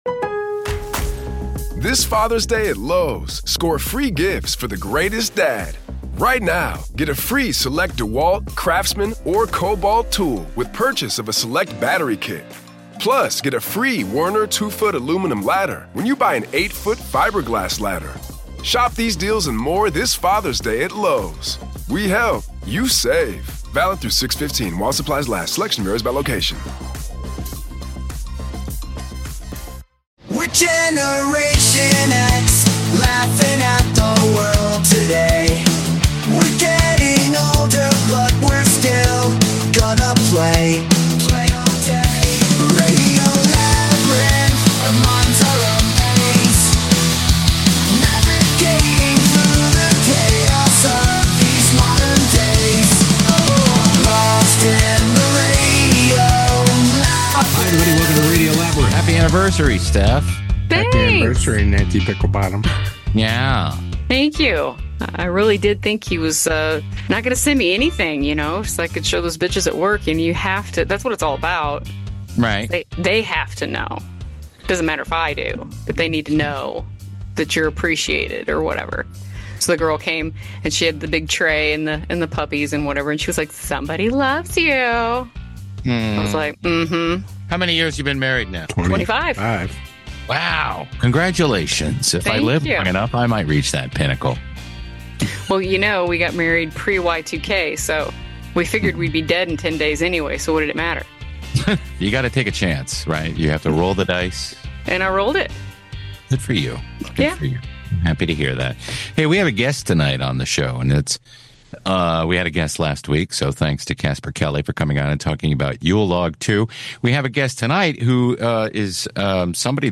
Corey Feldman Interview Going Viral
Our guest is none other than Corey Feldman—the iconic 80s actor from all your childhood favorites. Corey joins us to talk about his new film, Going Viral, which revisits the unforgettable 80s era.